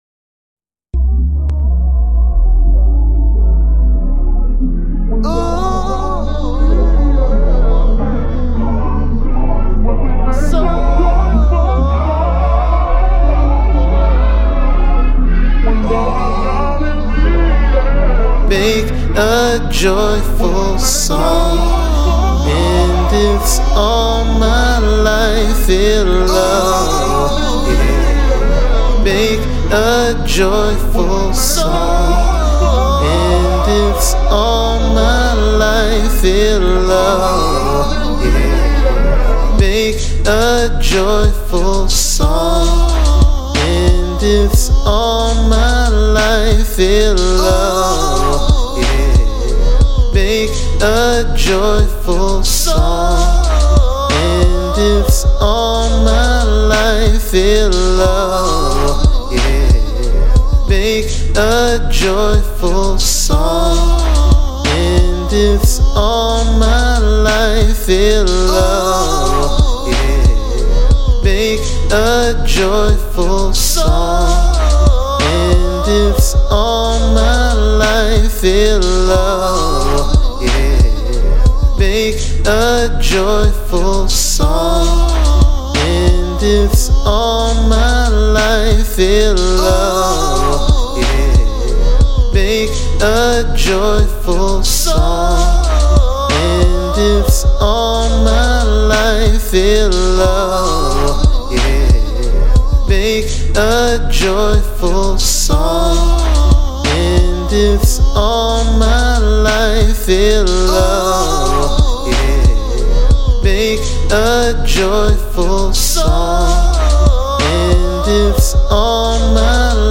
91 BPM. Random attempt at me trying and failing to pretend I can sing again..
randb soul
lofi hip hop rap singing garageband vocals chill adlibs background blues atmospheric jazzy trap 2000s interlude
Just practice those pitches and minimize those voice cracks, lol.
After re-listening to this, I was trying way too hard to sound like a 90s r&b singer, lol.